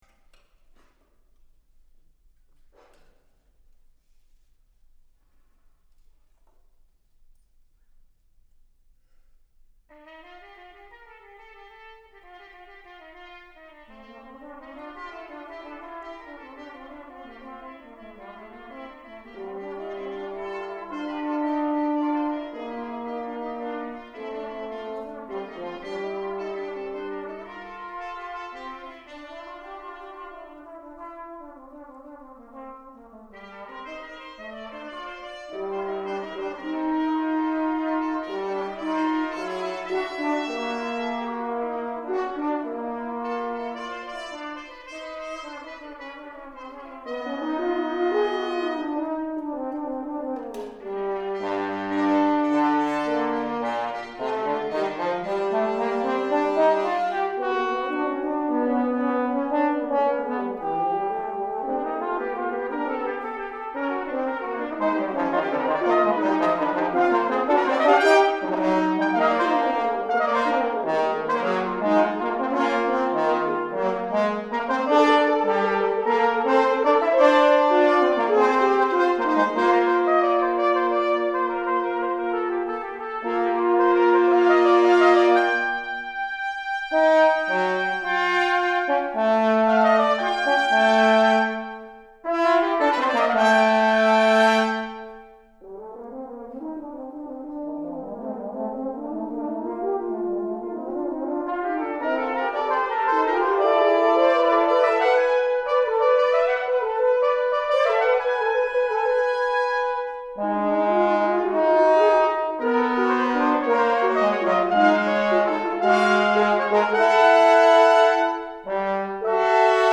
trumpet
trombone